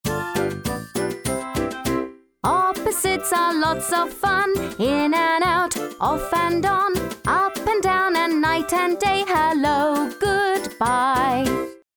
Clear and Concise Native Londoner with Cutting Edge Tones.
Singing, Children's Song
RP ('Received Pronunciation')